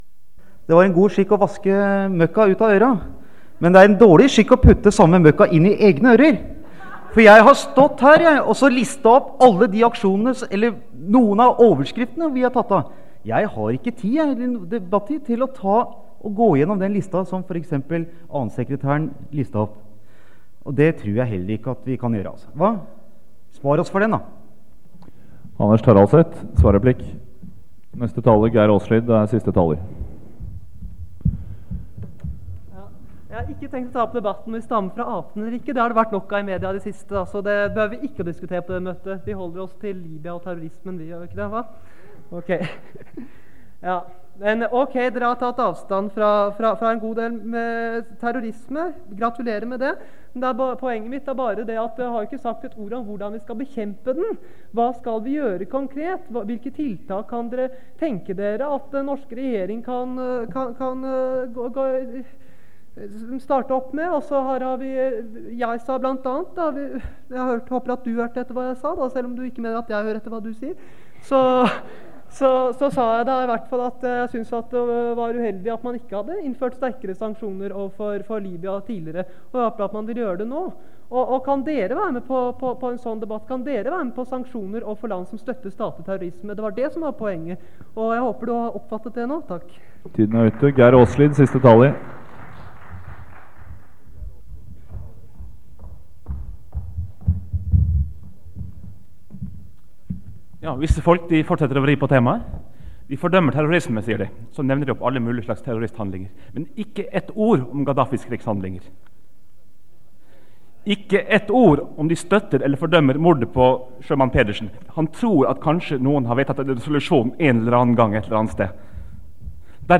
Det Norske Studentersamfund, Generalforsamling, 16.11.1986 (fil 3-4:4)
Generalforsamling (kassett)